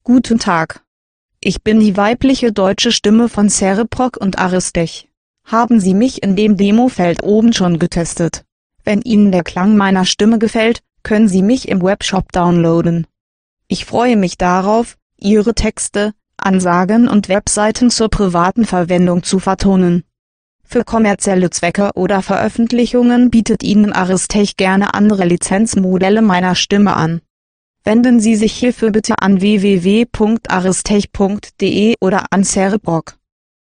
German Text to Speech Voices - MWS Reader
German Language voice Gudrun for Windows MS SAPI5, CereProc Ltd